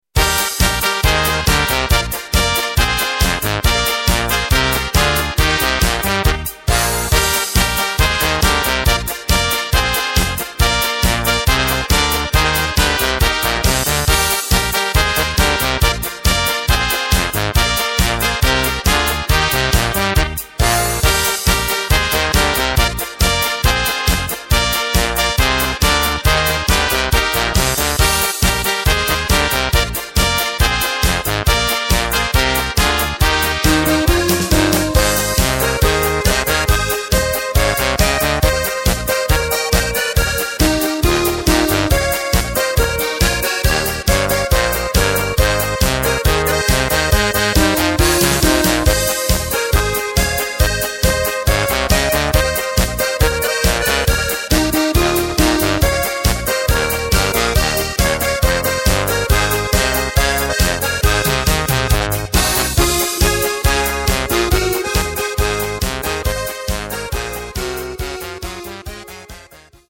Takt:          4/4
Tempo:         138.00
Tonart:            Eb
Polka aus dem Jahr 2019!